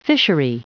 Prononciation du mot fishery en anglais (fichier audio)
Vous êtes ici : Cours d'anglais > Outils | Audio/Vidéo > Lire un mot à haute voix > Lire le mot fishery